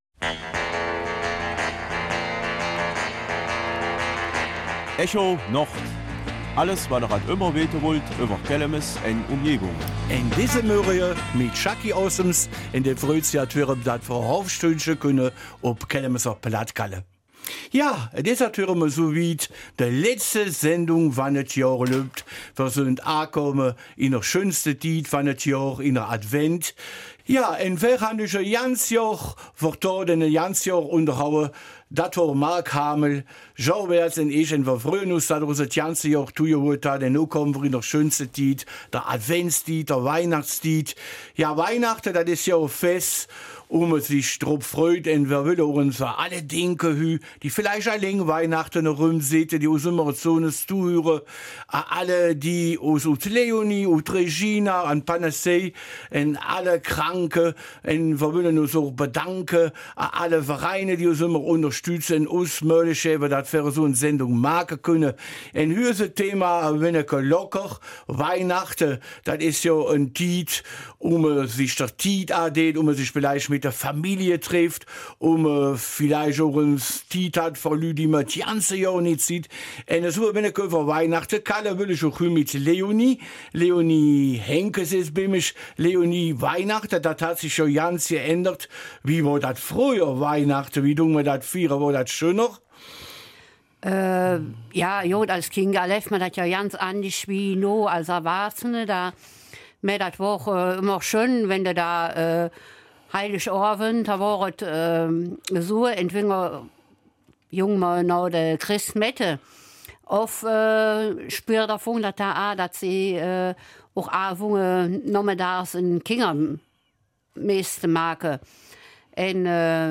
Kelmiser Mundart: Weihnachten